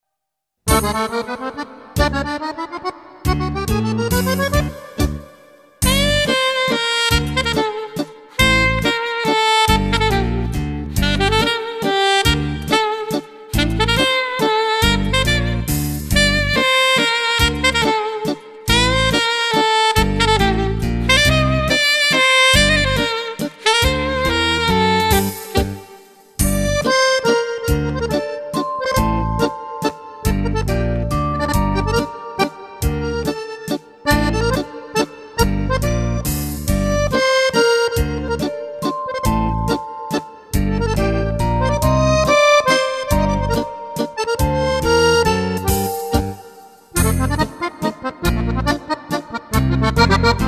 Mazurka